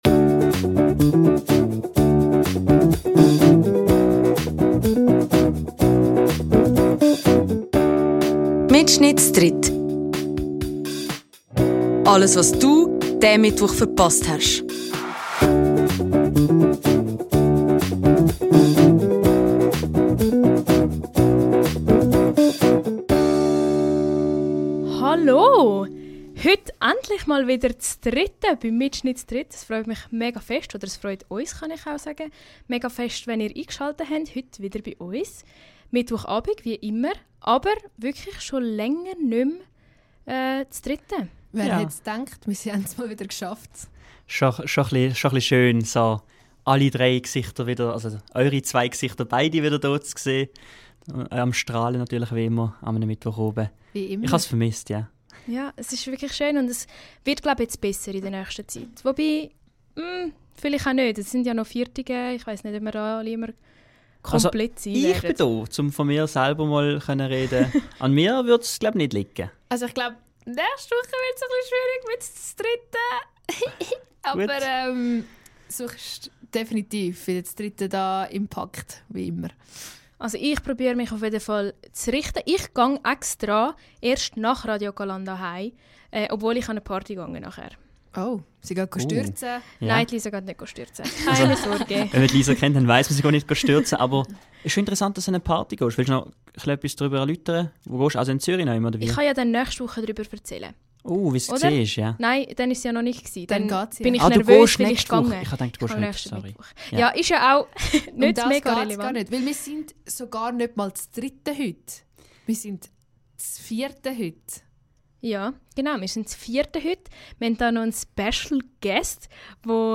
Endlich wieder vollzählig bi MittSchnitt z'dritt und trotzdem z'vierte im Studio.